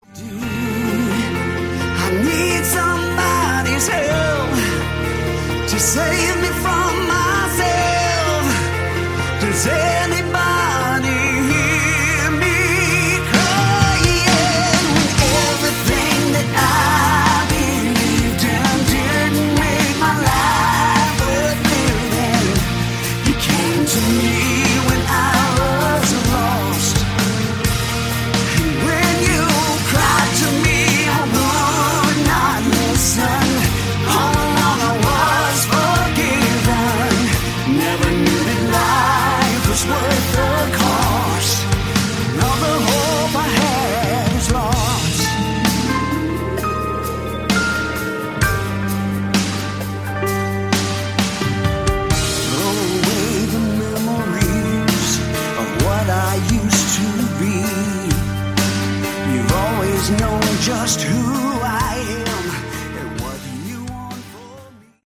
Category: Hard Rock
lead vocals, keyboards
guitars, vocals
drums
bass